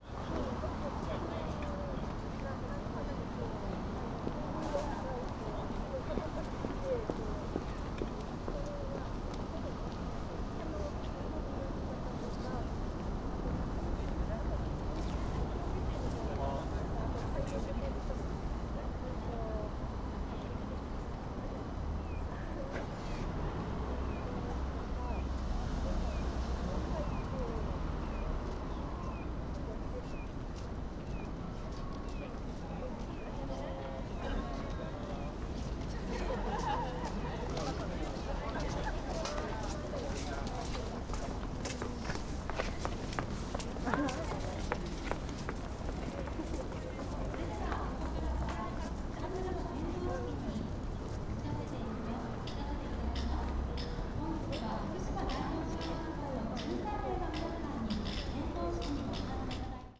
Fukushima Soundscape: Machi-naka Park